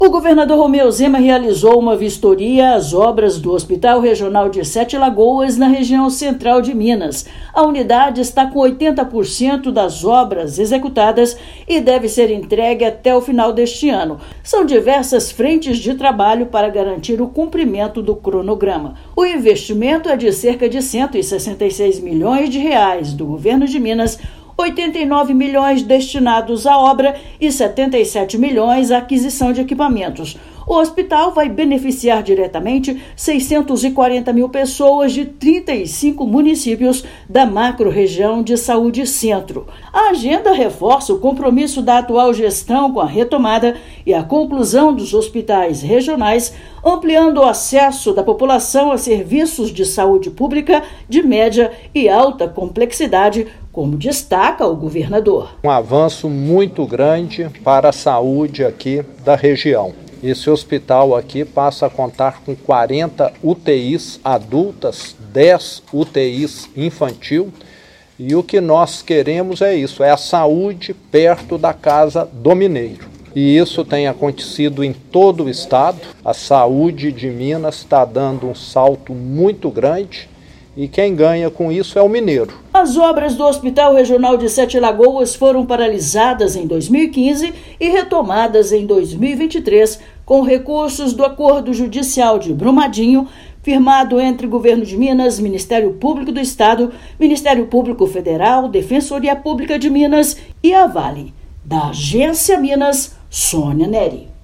[RÁDIO] Governo de Minas vistoria obras do Hospital Regional de Sete Lagoas
Com investimentos de R$ 166 milhões, unidade vai ampliar o atendimento de média e alta complexidade na região Central do estado. Ouça matéria de rádio.